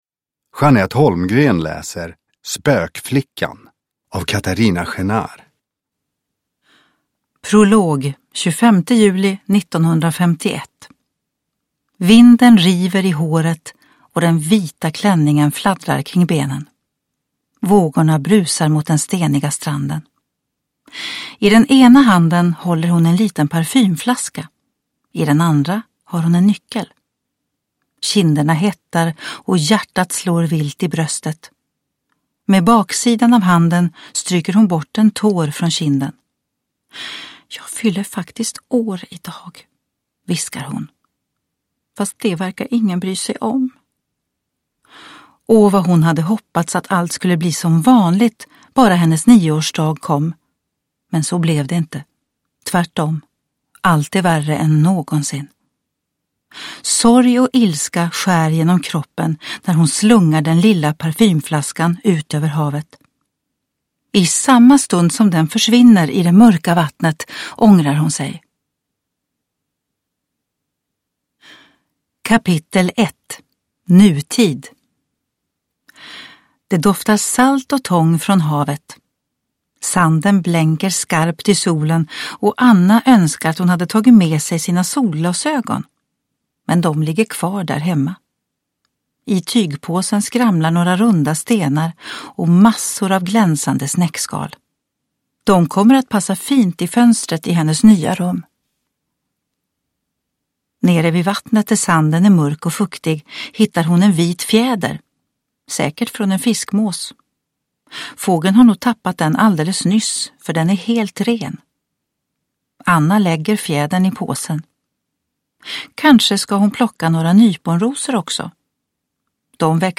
Spökflickan / Ljudbok